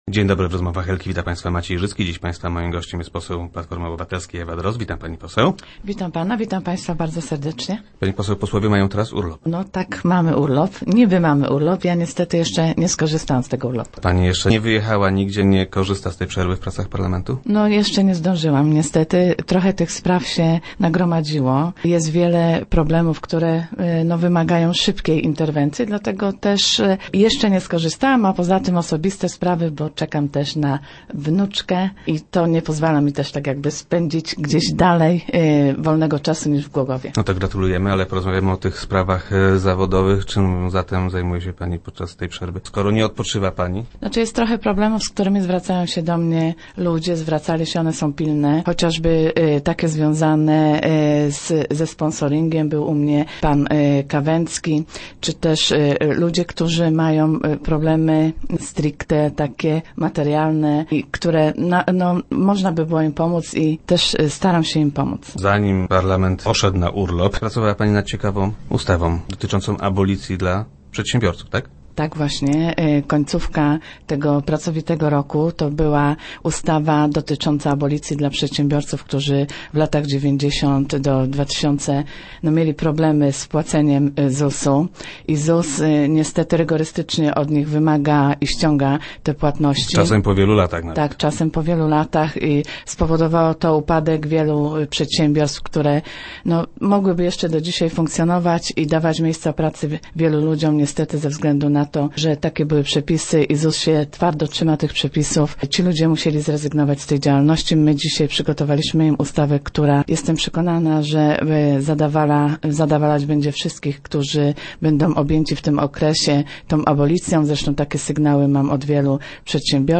Na brak zajęć nie narzeka posłanka Ewa Drozd, która była gościem poniedziałkowych Rozmów Elki.